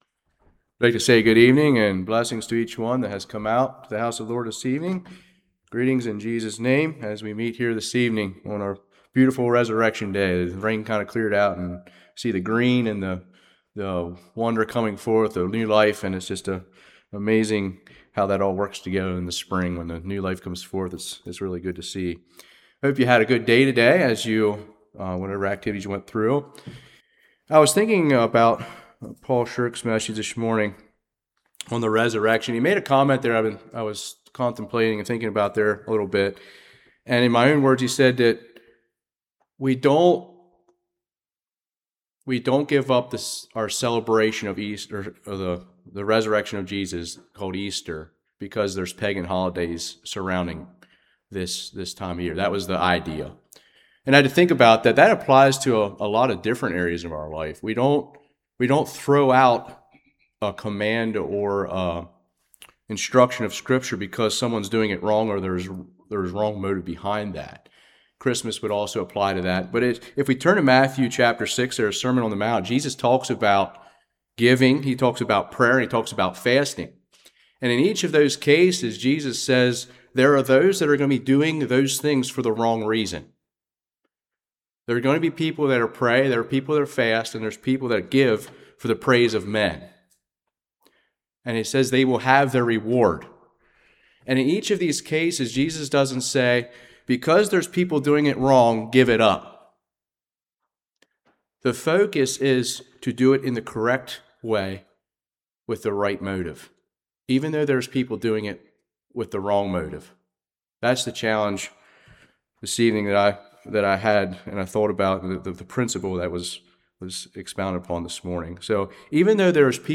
1 Cor 15:51-58 Service Type: Evening Matthew 28 What does it mean to us today